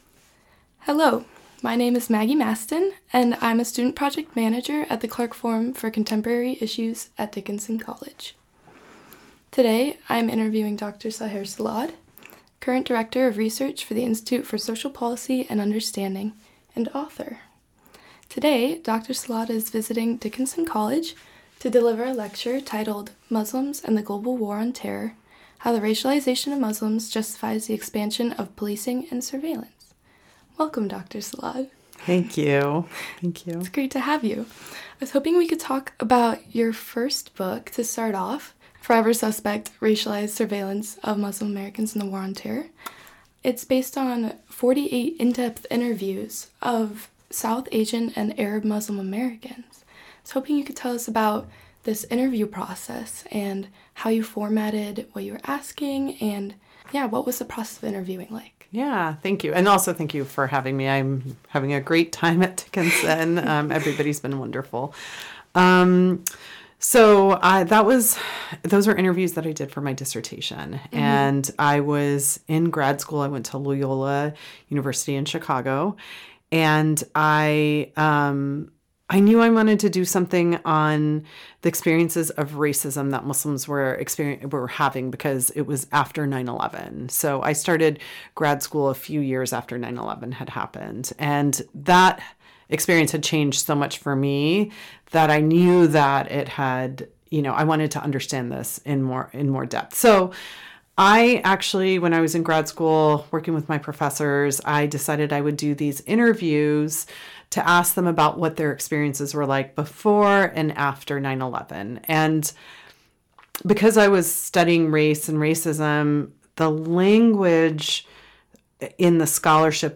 The Clarke Forum For Contemporary Issues Interview